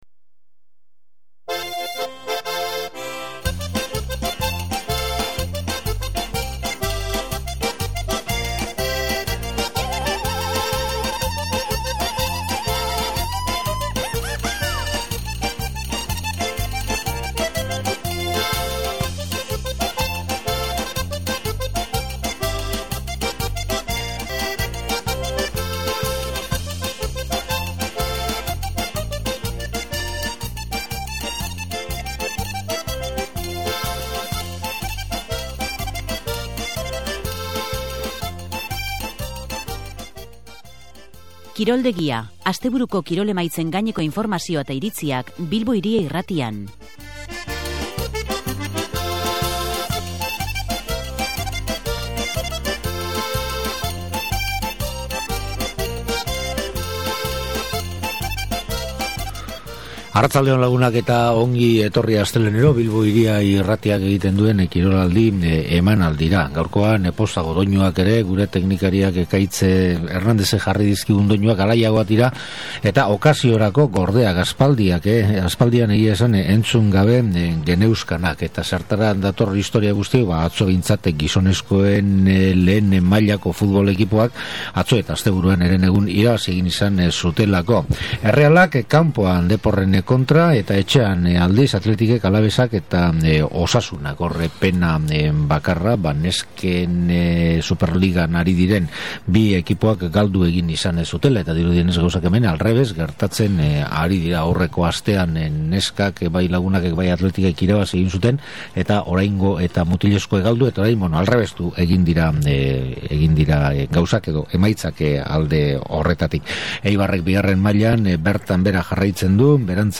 Dena den, Osasunak izan ezik, beste hirurek zuloan jarraitzen dute oraindik, eta horrek zer pentsatua eta zer esana eman die gure forofogoitiei. Emakumezkoen Superligan ezin gauza bera esan, ordea, Lagunak eta Athletic taldeek galdu egin baitzuten. Bestalde, esan behar pilota arloan Ordagok, Asegarcek eta Besagainek antolaturiko torneo berria ekarri dutela hizpidera gure kirol komentaristek.